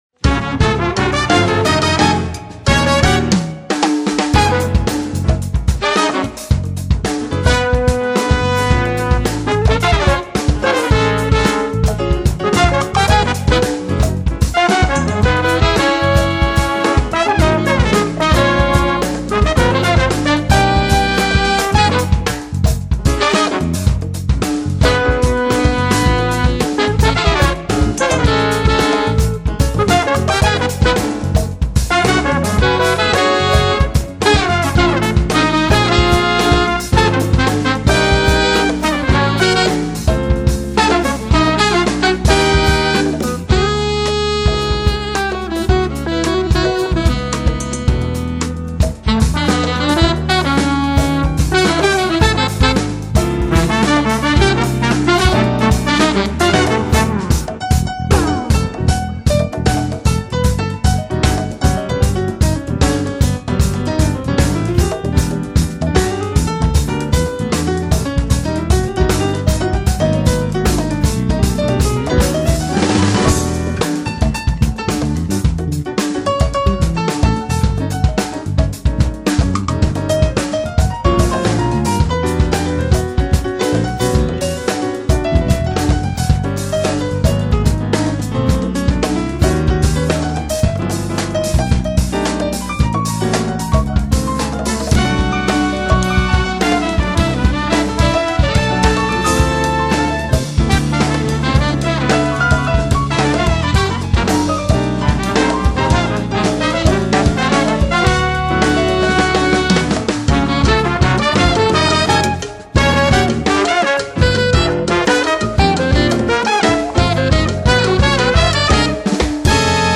latin fusion octet
sax
trumpet
trombone
guitar
drums
percussion